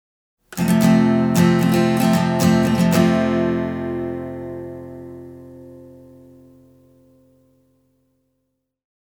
なので実際に弾いてみました。
普通のＦコード
Ｆのコード感はどちらもある感じがしませんか？